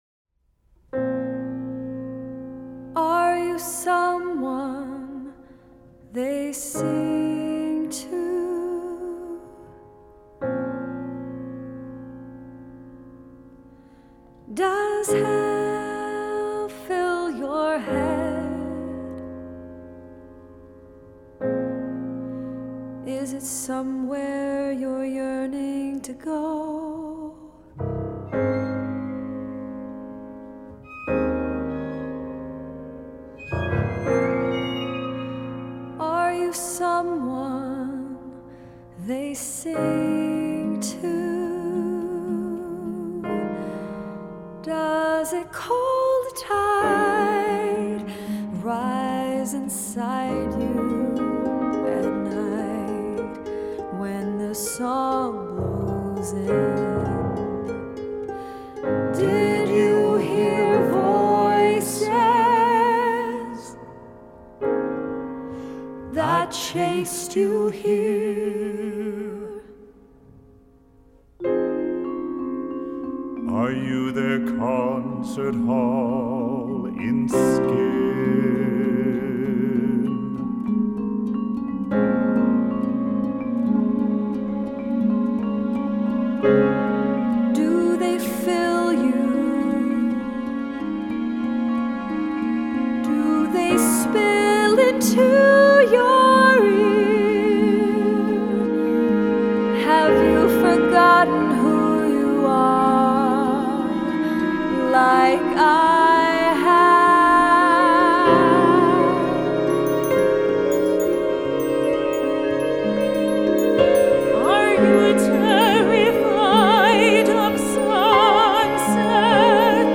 Flute
Harp
Cello